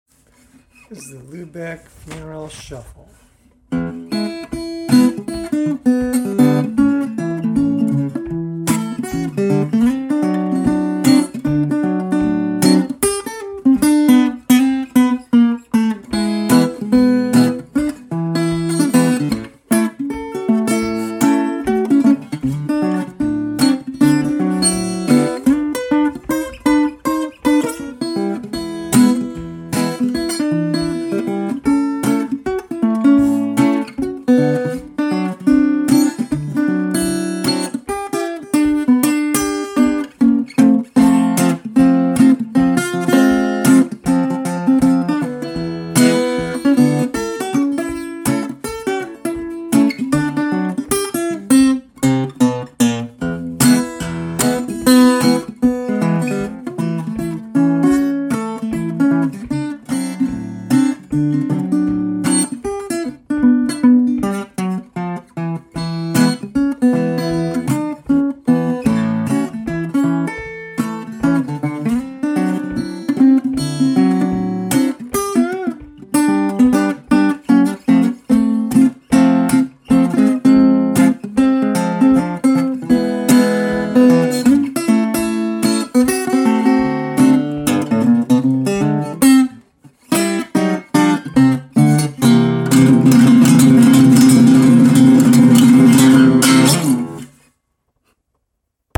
a little acoustic guitar two-step with a flat-4.